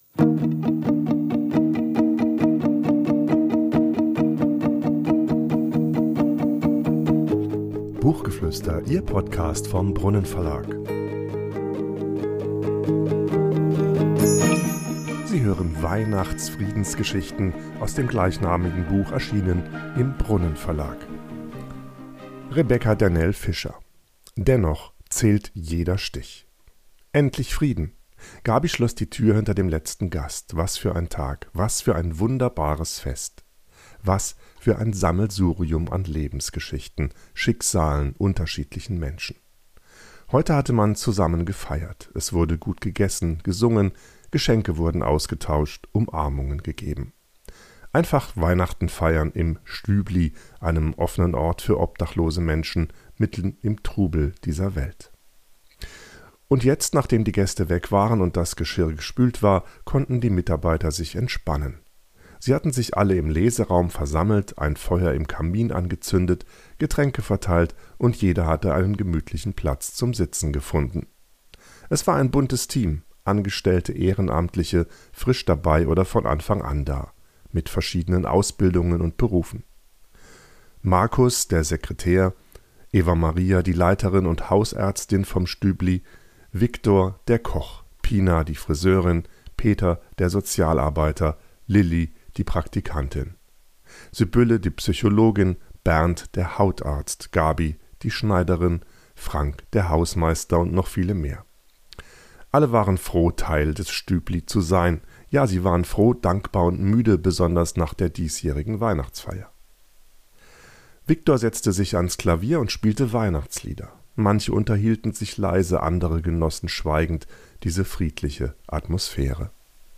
»WeihnachtsFriedensGeschichten« vorliest.